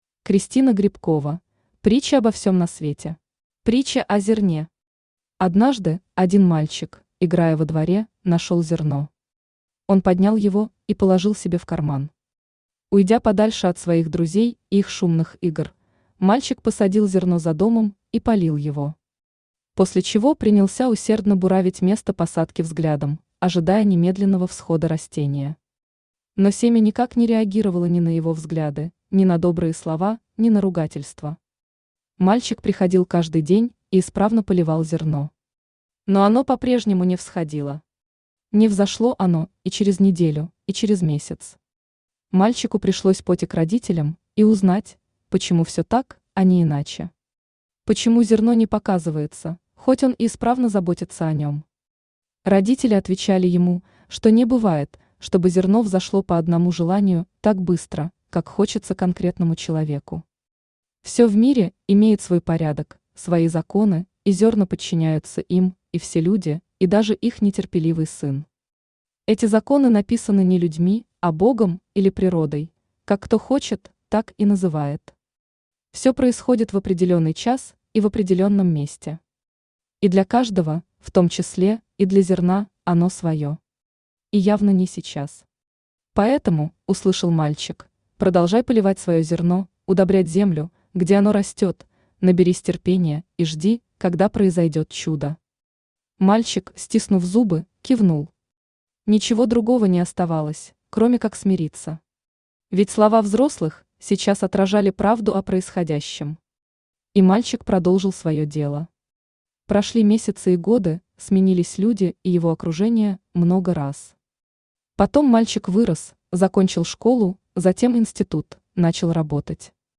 Aудиокнига Притчи обо всем на свете Автор Кристина Грибкова Читает аудиокнигу Авточтец ЛитРес.